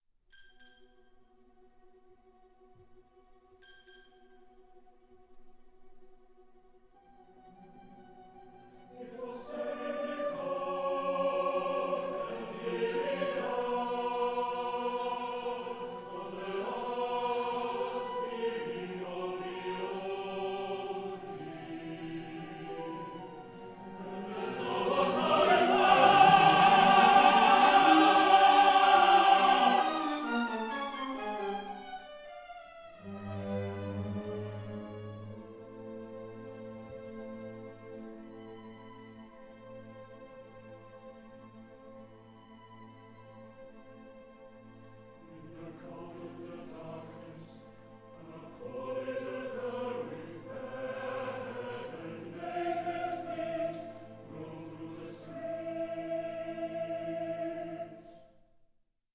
Stereo/Multichannel
for chorus and orchestra